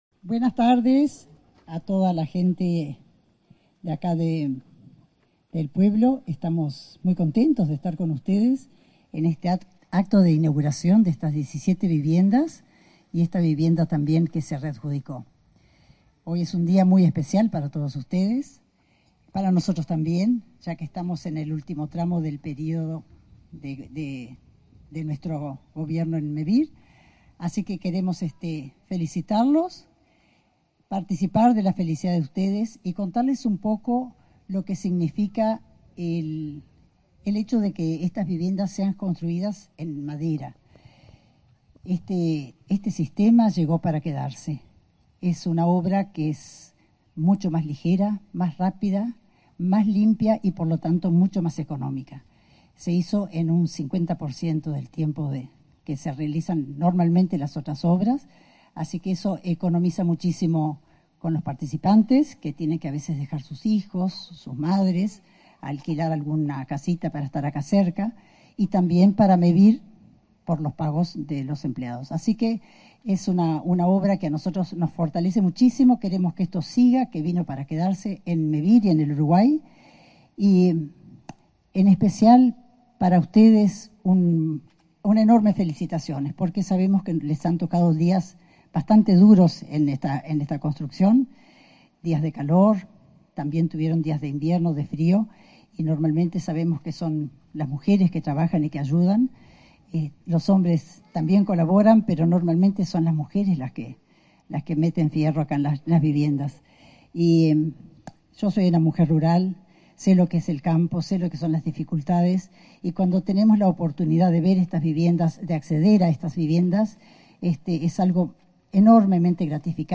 Palabras en la ceremonia de entrega de viviendas en la localidad 18 de Julio
Palabras en la ceremonia de entrega de viviendas en la localidad 18 de Julio 24/02/2025 Compartir Facebook X Copiar enlace WhatsApp LinkedIn En el marco de la ceremonia de entrega de 17 viviendas construidas en madera en la localidad 18 de Julio, este 24 de febrero, se expresaron la presidenta de Mevir, Cristina Secco, y el subsecretario de Vivienda y Ordenamiento Territorial, Tabaré Hackenbruch.